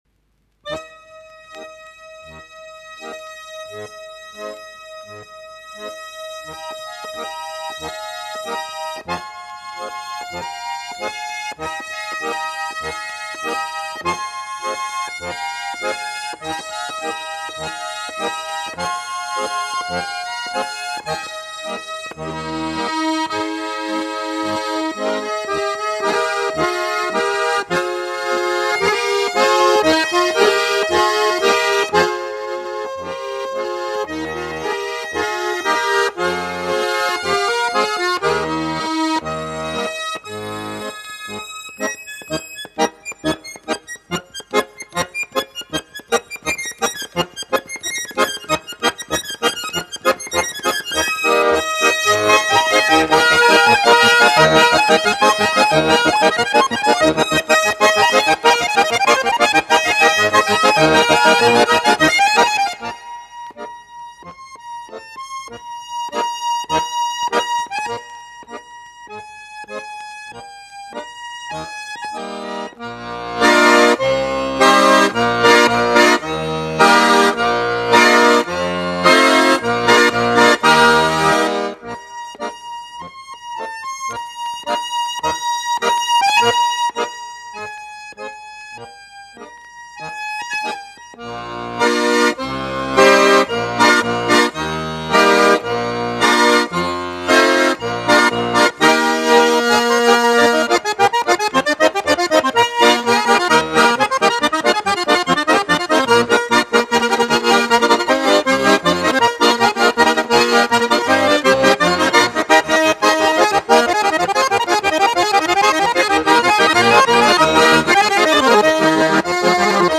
Гармонь: